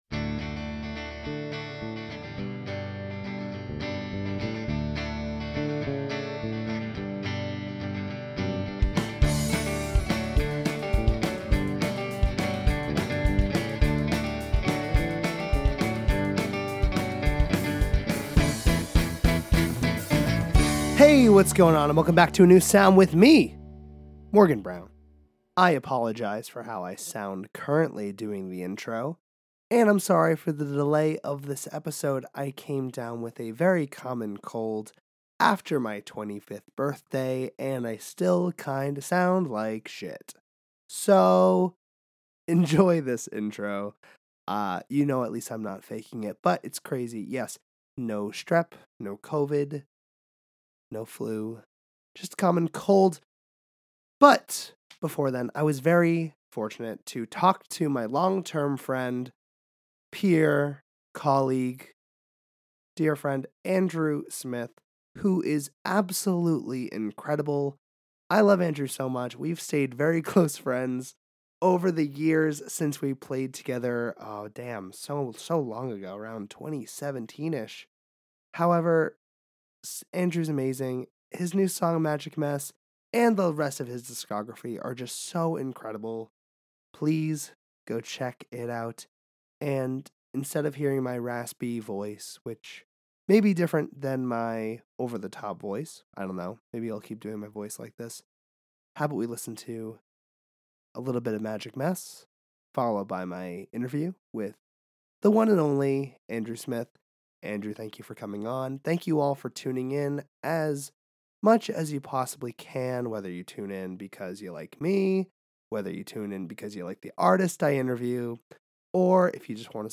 Sorry about my raspy voice and the delay on release!